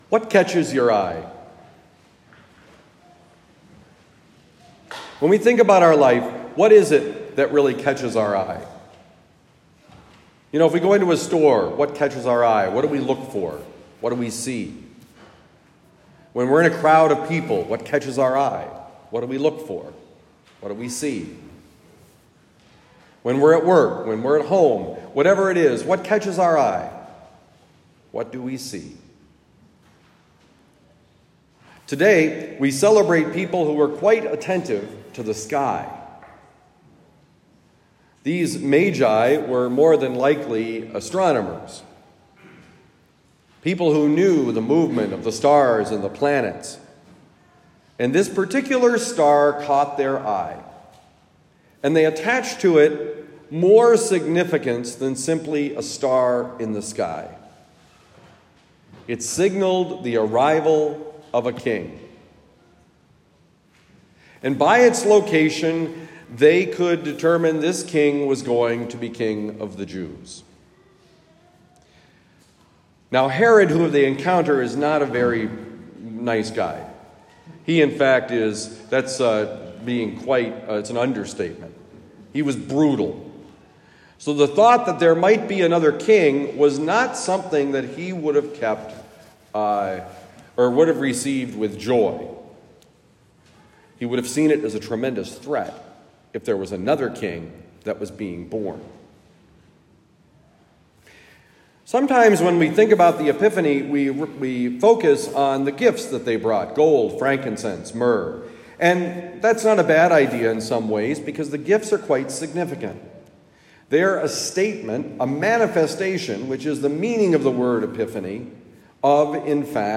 Homily given at Our Lady of Lourdes Parish, University City, Missouri.